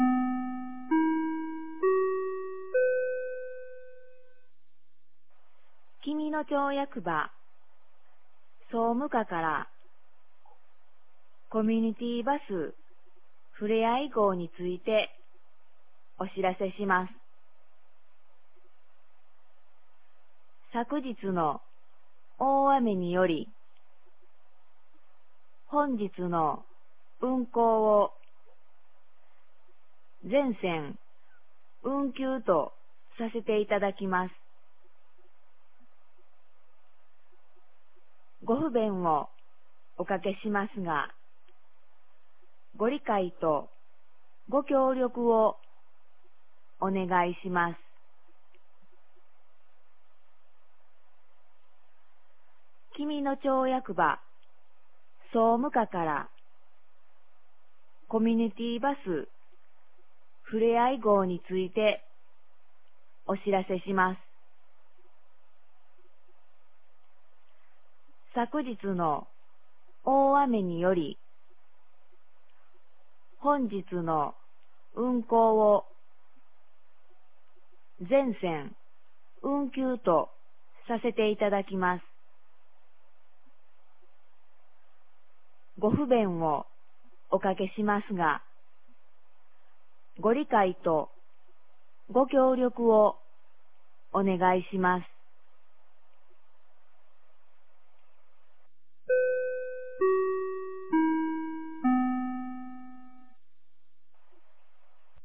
2023年06月03日 06時31分に、紀美野町より全地区へ放送がありました。